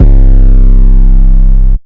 Gezin 808 15.wav